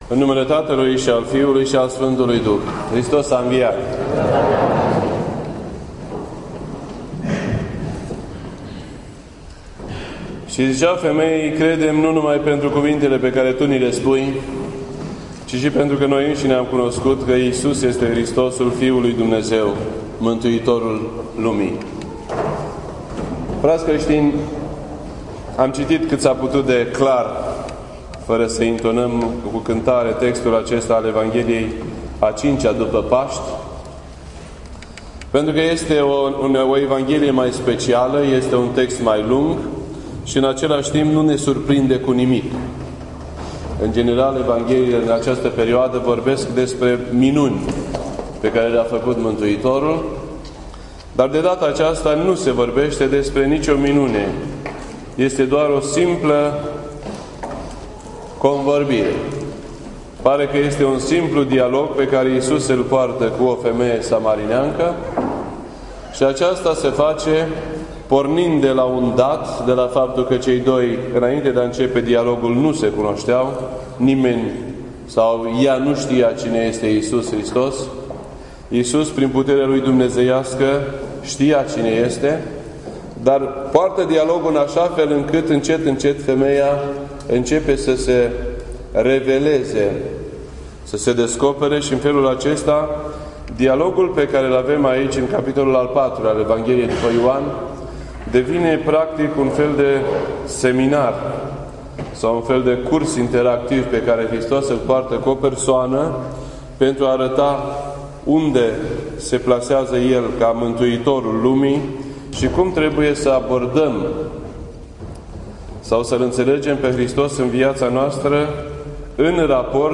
This entry was posted on Sunday, May 10th, 2015 at 4:04 PM and is filed under Predici ortodoxe in format audio.